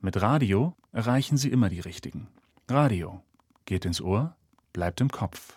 vielseitig, vertrauensvoll, sonor, jung, warm, markant, ausdrucksstark, authentisch, seriös, werbend, humorvoll, wiedererkennbar
Sprechprobe: eLearning (Muttersprache):